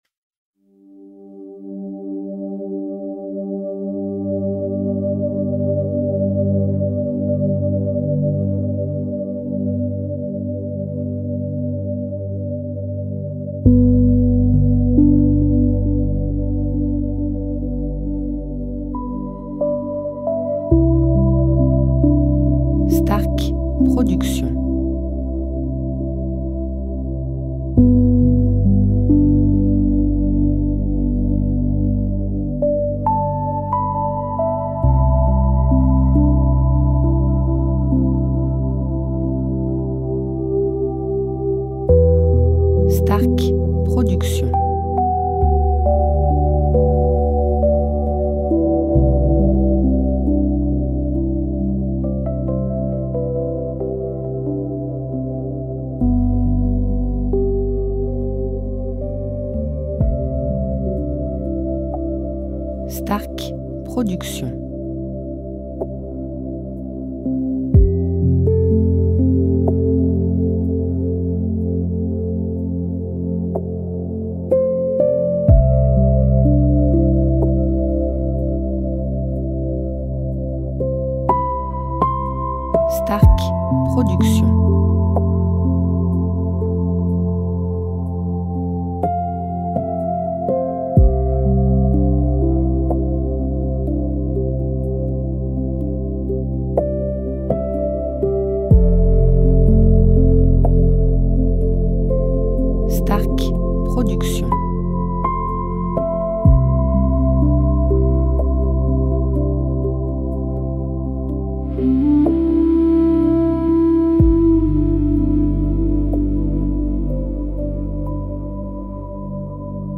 style Relaxation Worldmusic durée 1 heure